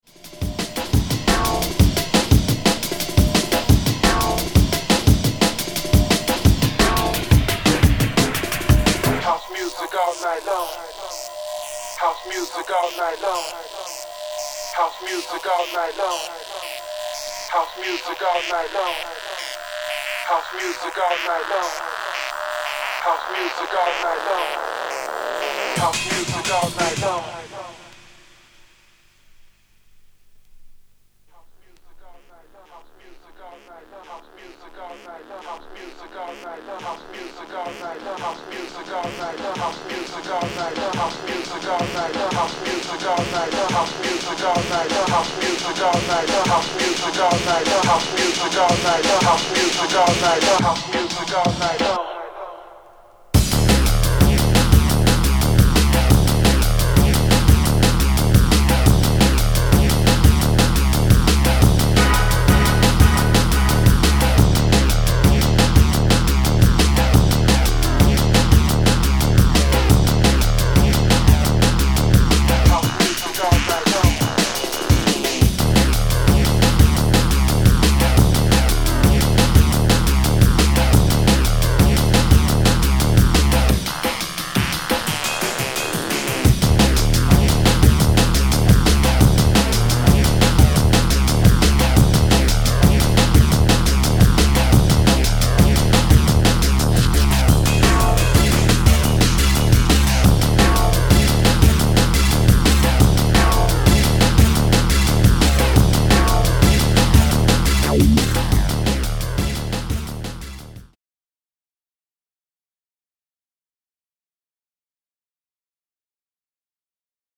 Jungle/Drum n Bass
Drum & Bass